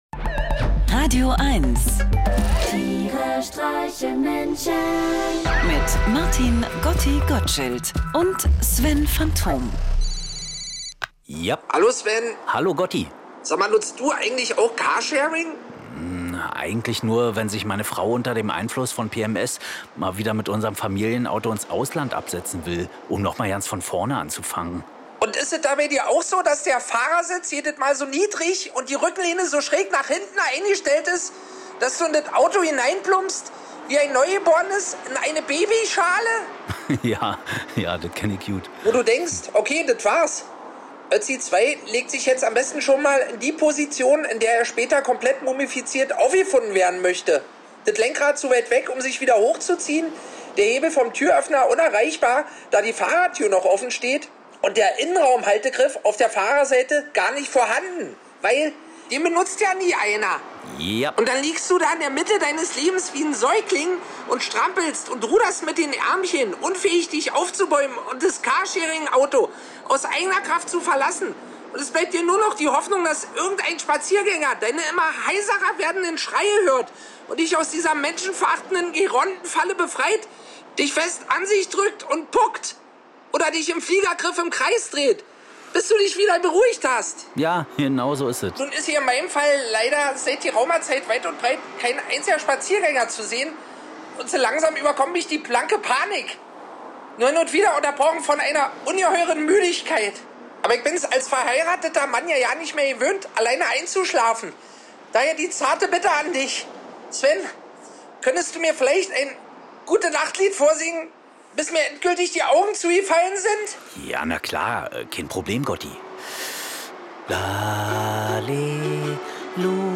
Einer liest, einer singt und dabei entstehen absurde, urkomische, aber auch melancholische Momente. Irgendwie mitten aus dem Leben und irgendwie bekloppt.